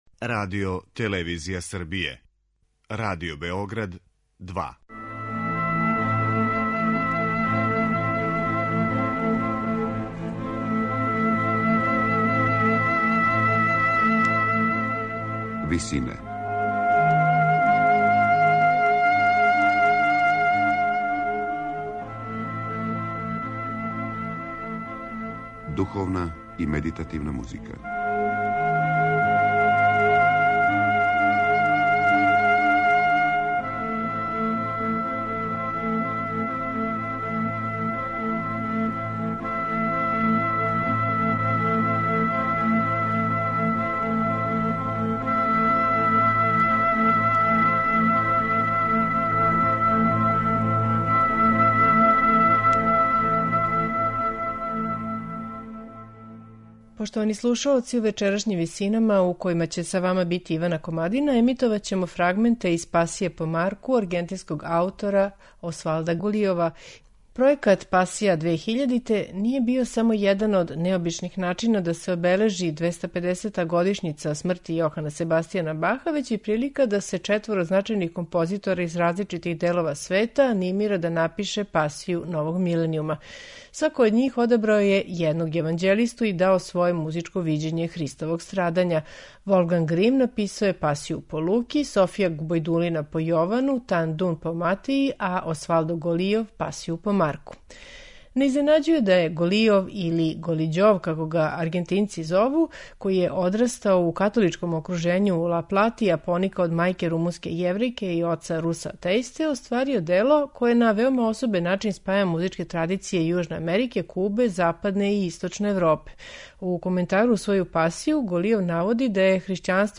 бразилска џез певачица
афрокубански музичар
медитативне и духовне композиције